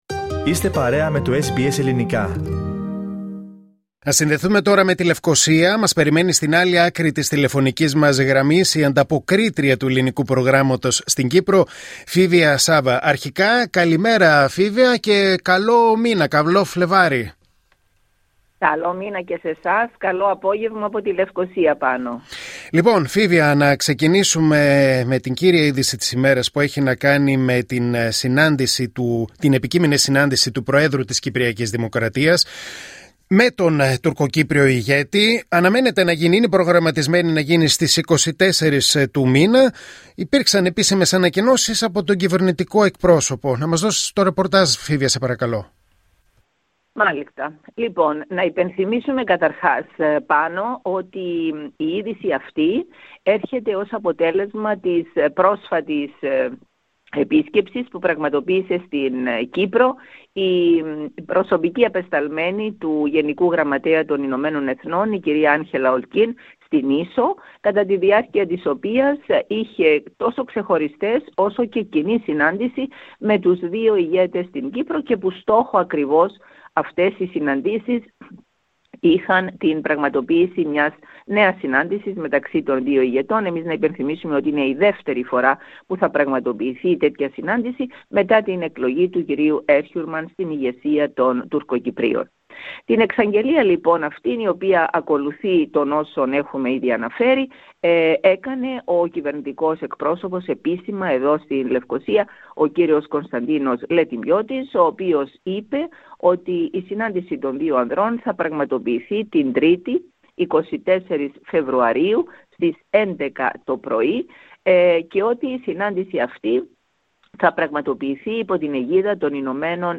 Ακούστε αναλυτικά την ανταπόκριση από την Κύπρο πατώντας PLAY δίπλα από την κεντρική εικόνα.